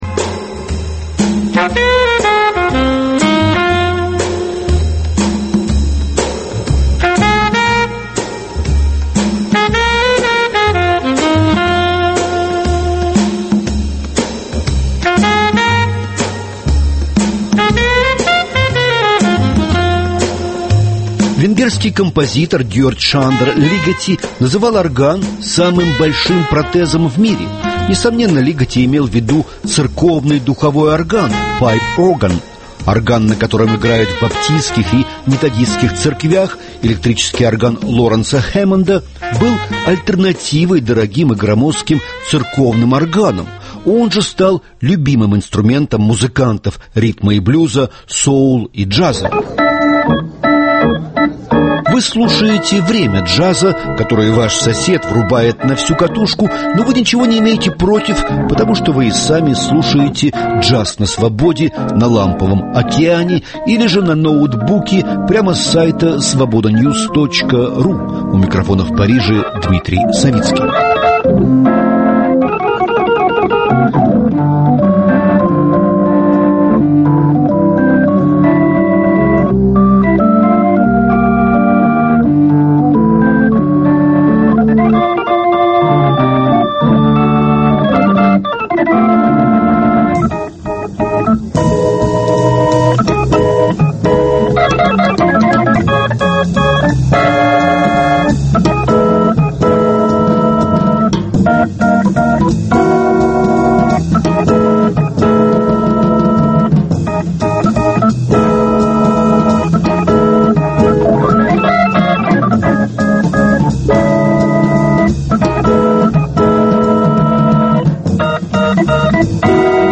органист
трубач и флюгельгорнист
пианист и бандлидер